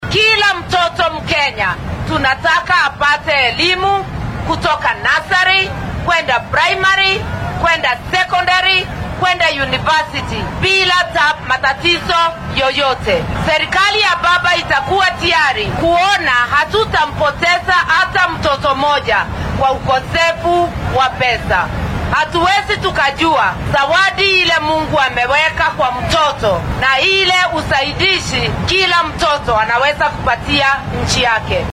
Ku xigeenka musharraxa madaxweyne ee isbeheysiga Azimio La Umoja-One Kenya , Martha Karua ayaa shalay isku soo bax siyaasadeed ku qabatay magaalada Eldoret ee ismaamulka Uasin Gishu.